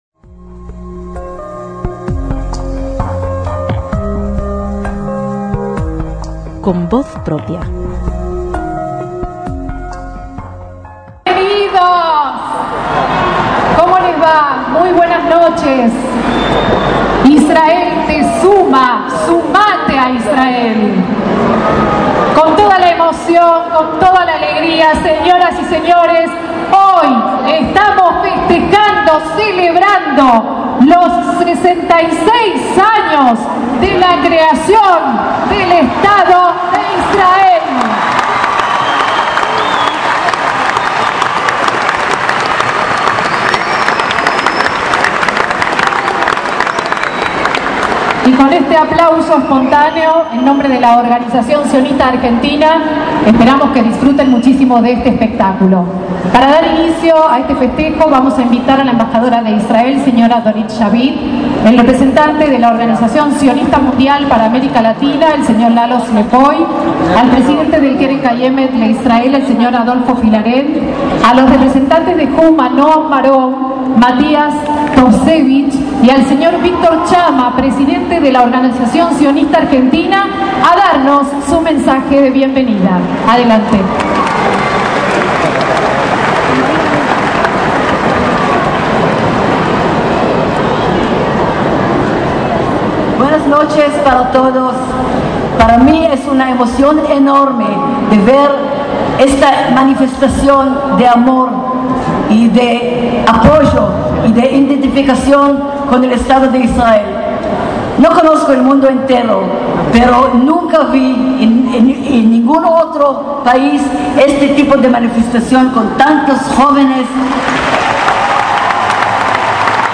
Acto central de Yom haAtzmaut en Buenos Aires (Luna Park, 5/5/2014)
El acto se inició con las palabras alegóricas de algunos de los referentes comunitarios y un conmovedor himno nacional argentino entonado por Valeria Lynch, y se cantó el Hatikva.